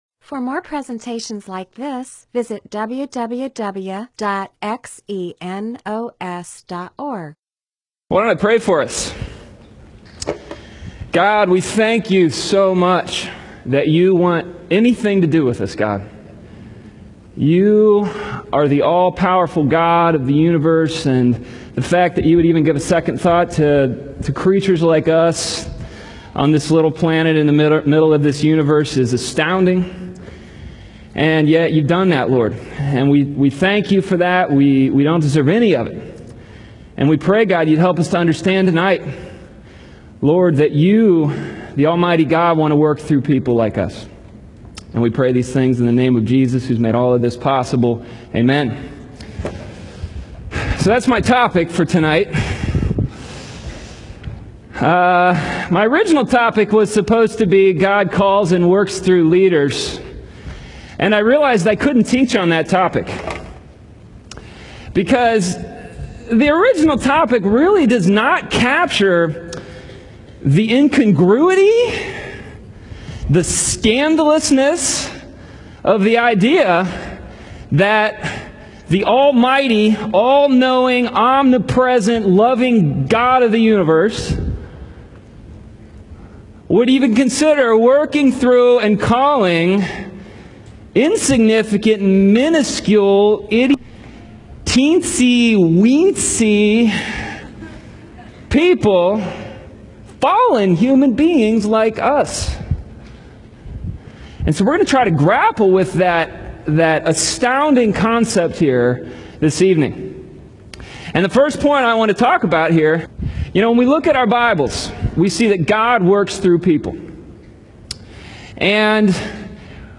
MP4/M4A audio recording of a Bible teaching/sermon/presentation about .